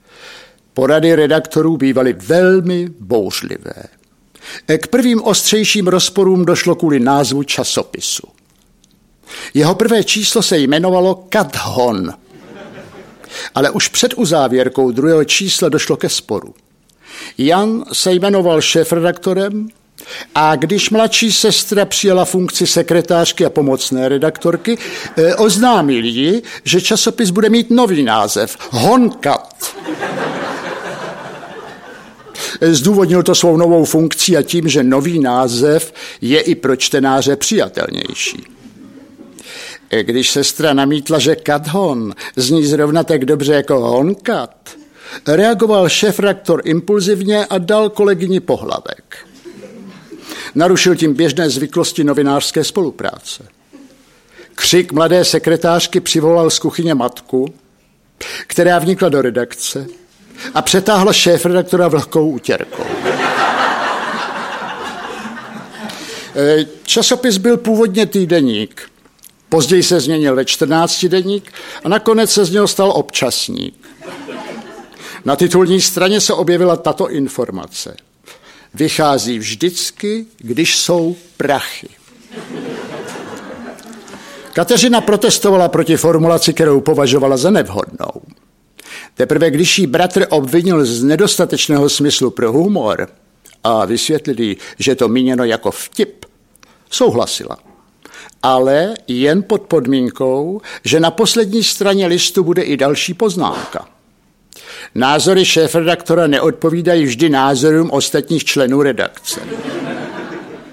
Ukázka z knihy
Bratři Krausové se již potřetí sešli na jevišti pražského divadla Viola k četbě humoristických povídek.
Herec a moderátor Jan Kraus a spisovatel Ivan Kraus interpretují příběhy, jejichž aktéry byli oni sami. Během představení tedy není nouze ani o glosování některých událostí.